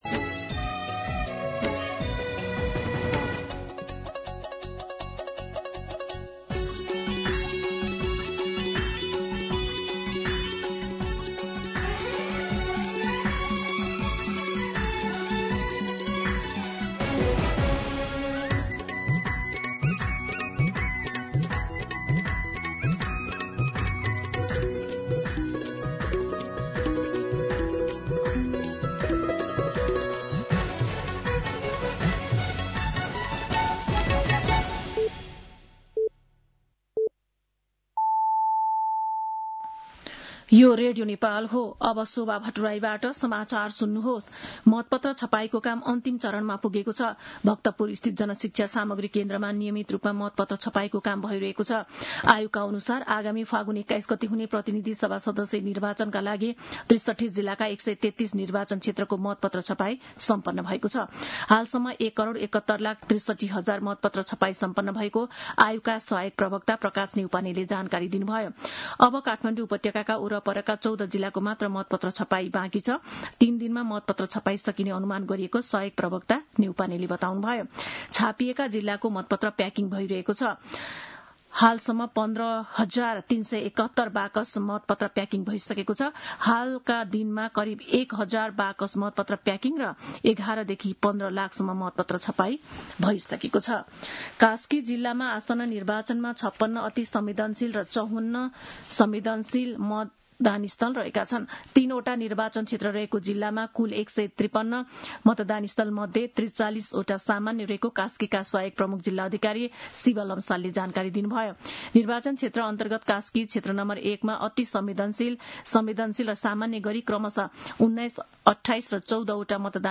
मध्यान्ह १२ बजेको नेपाली समाचार : २ फागुन , २०८२
12-pm-Nepali-News-2.mp3